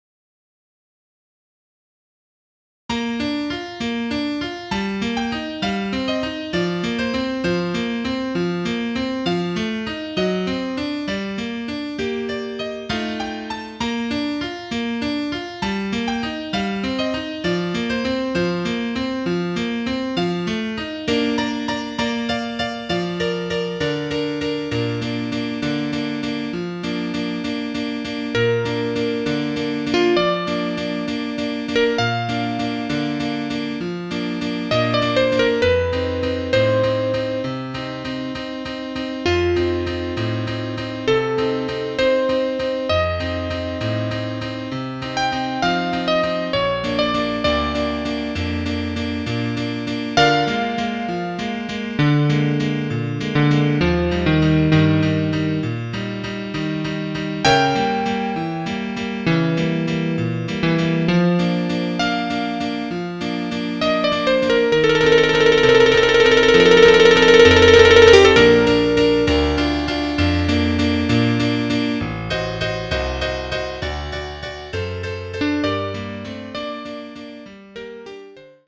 für Klarinette und Klavier,
Hörbeispiel (B-Dur):